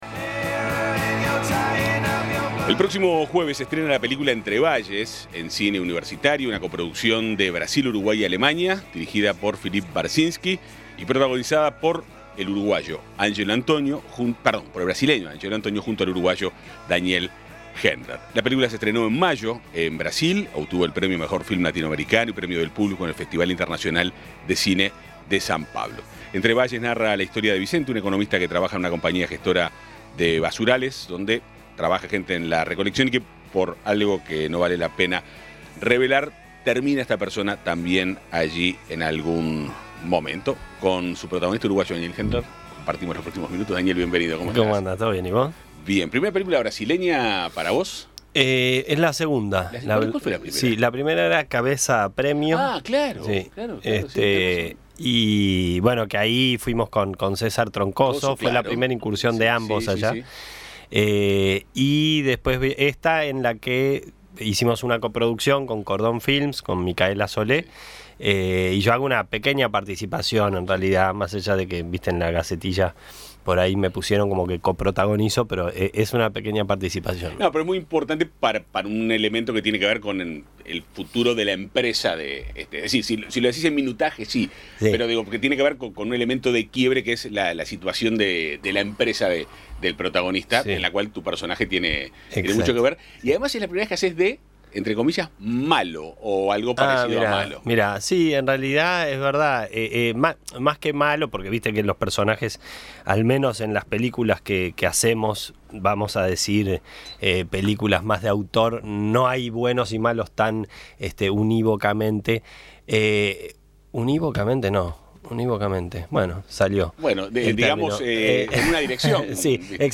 Daniel Hendler visitó los estudios de El Espectador y adelantó detalles de "Entre valles", película que hoy se estrena en el Cine Universitario. El actor repasó qué proyectos en televisión y propuestas para hacer cine tiene en la mira; la difícil relación con la exposición mediática y los retos que asumió en su última producción.
Descargar Audio no soportado Entrevista a Daniel Hendler Philippe Barcinski dirigió "Entre valles";, película protagonizada por el brasileño Ángelo Antonio y el uruguayo Daniel Hendler.